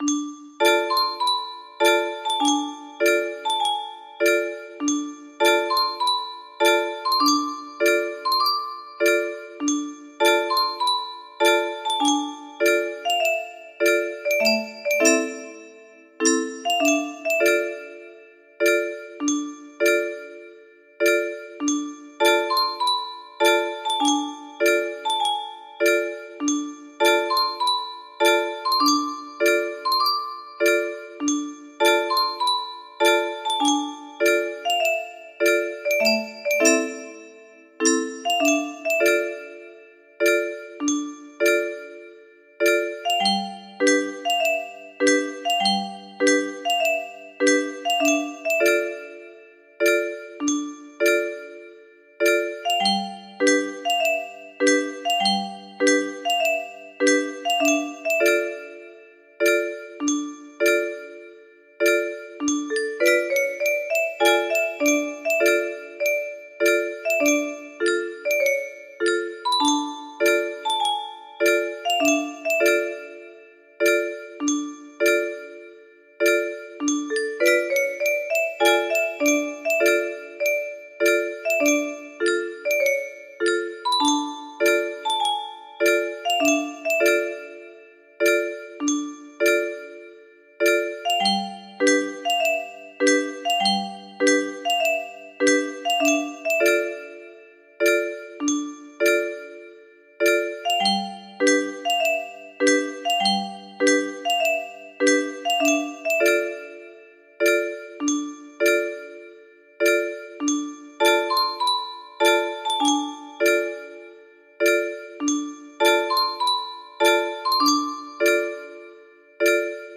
Erik Satie - Gnossiennes No. 1 music box melody
I've since lowered the octave in order to achieve the correct notes.
I've also sped up the tempo just so the piece doesn't take so long to play on the website. But this composition played at a faster tempo makes it more cheerful.
Lastly there is a lot of repetition in this piece.